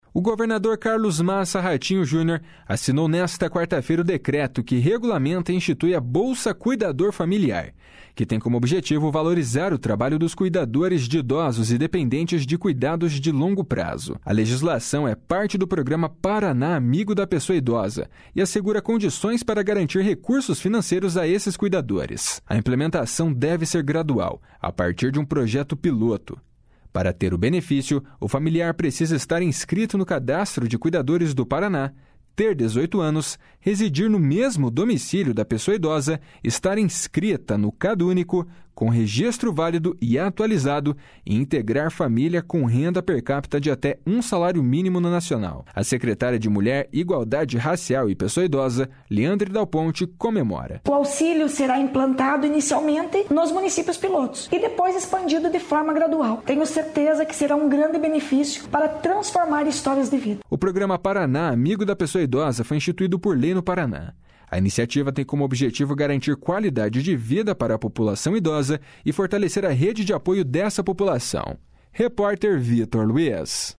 A secretária de Mulher, Igualdade Racial e Pessoa Idosa, Leandre dal Ponte, comemora. // SONORA LEANDRE DAL PONTE //